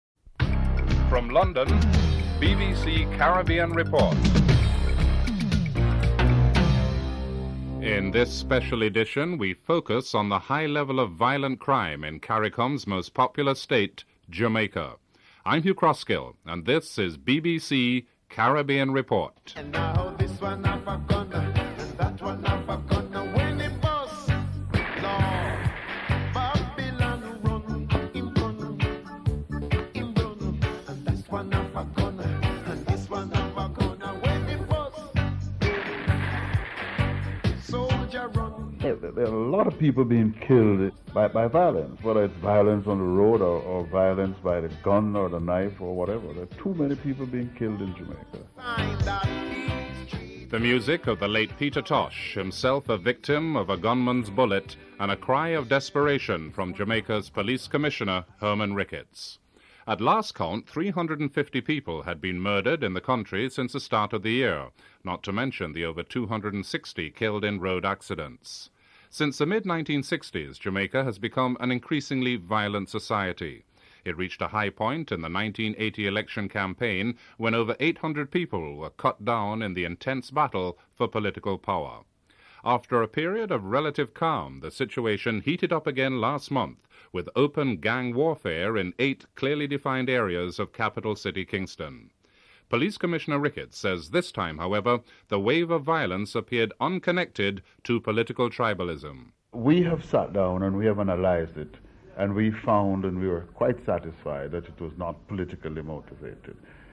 Report begins and ends with music by Peter Tosh.